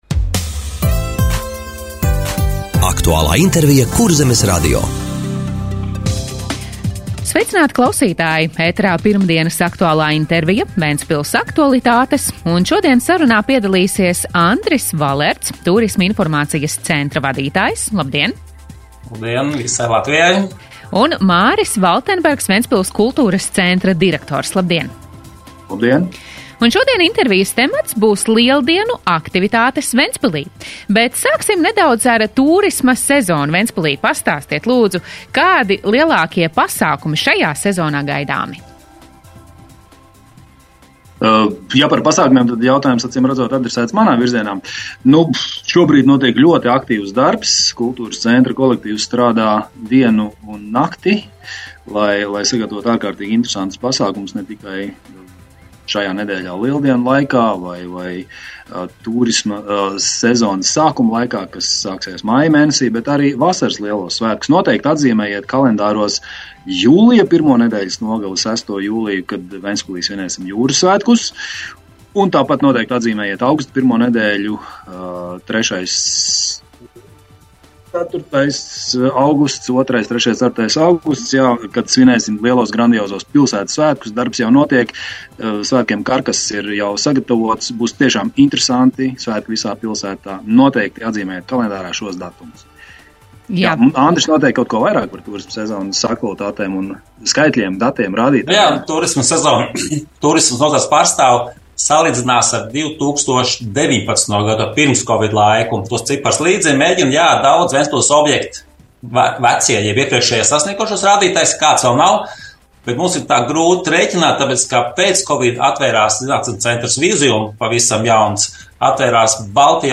Radio saruna Lieldienu aktivitātes Ventspilī